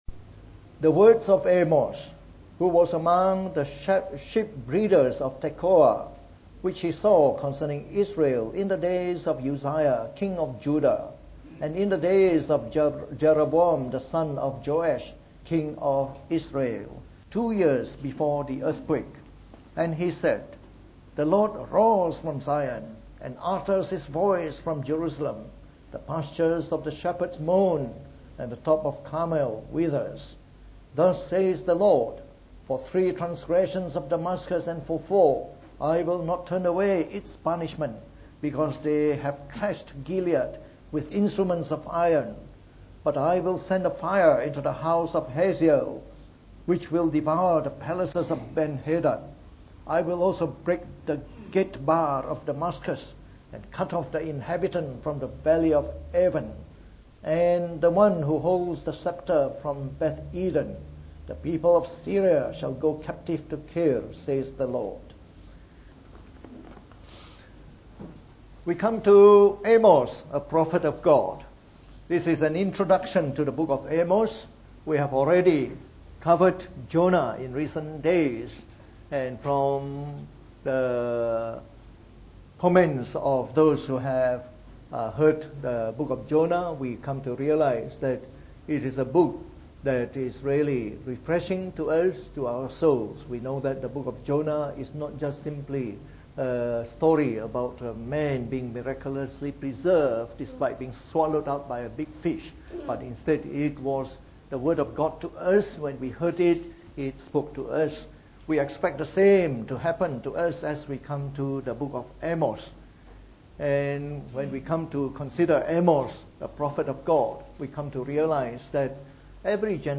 From our new series on the Book of Amos delivered in the Morning Service.